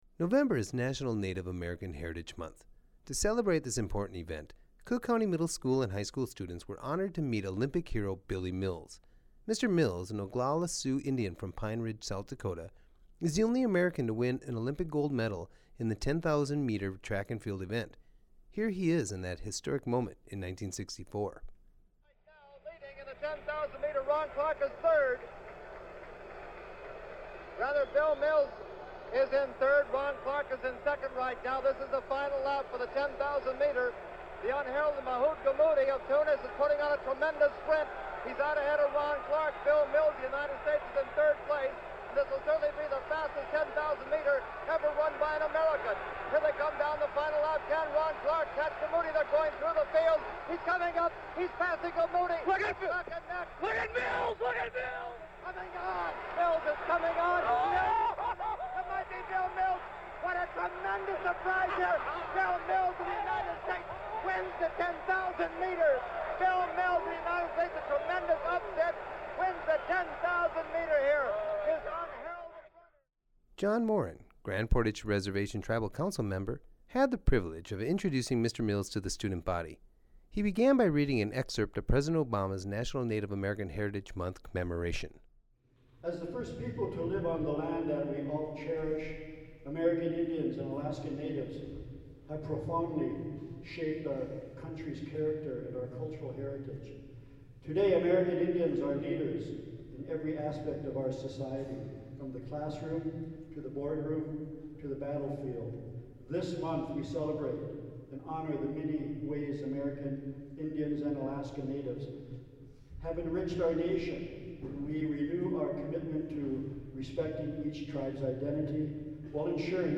Oglala Lakota Olympic gold medalist Billy Mills speaks with local students